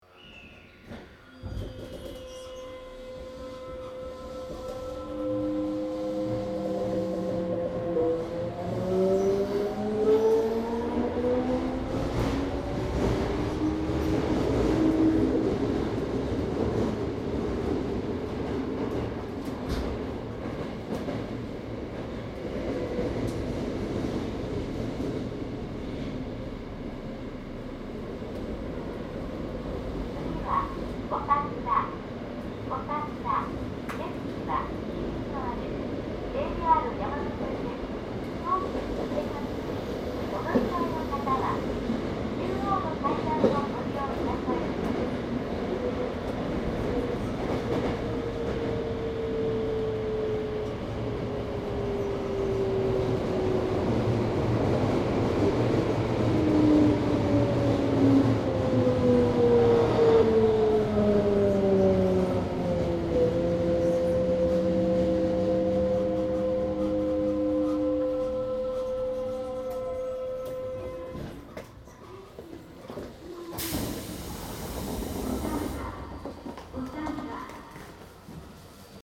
5300形はソフト変更前の三菱？の走行音です。
■【各停】泉岳寺→西馬込  5314-1
サンプル音声 5314-1.mp3
マスター音源はデジタル44.1kHz16ビット（マイクＥＣＭ959）で、これを編集ソフトでＣＤに焼いたものです。